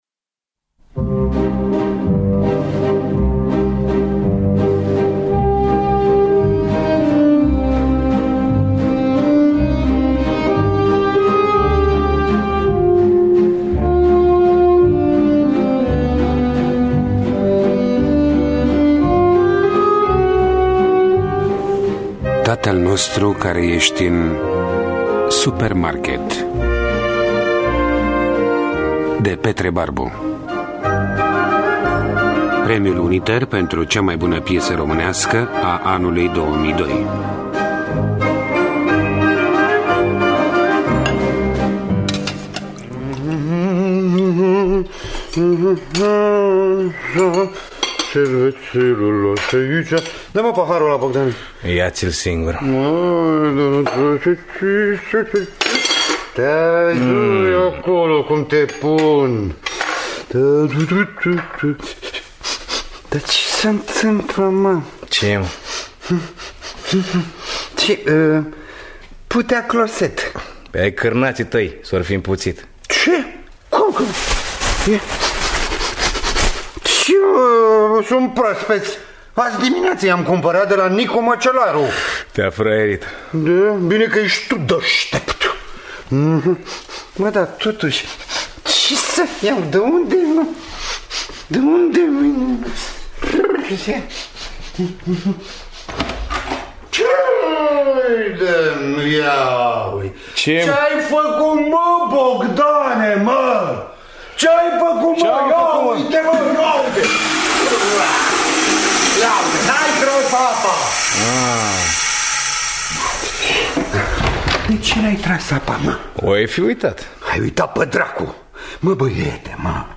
Tatăl nostru care ești în supermarket… de Petre Barbu – Teatru Radiofonic Online